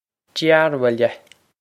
Dearbhaile Dur-vil-eh
Pronunciation for how to say
This is an approximate phonetic pronunciation of the phrase.